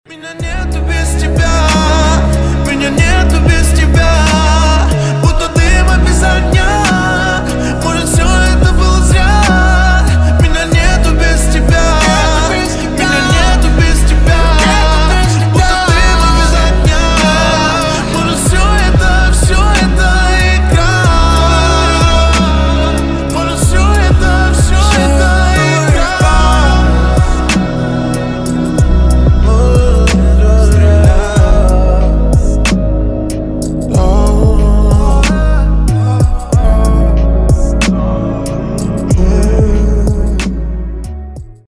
• Качество: 320, Stereo
мужской голос
лирика